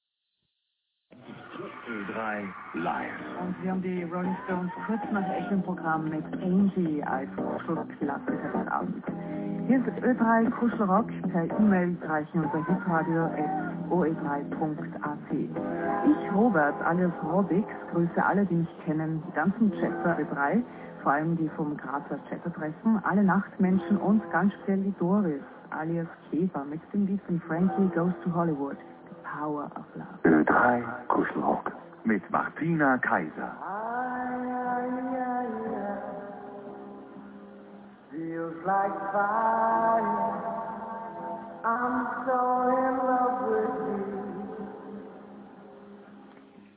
Mitschnitt als MP3).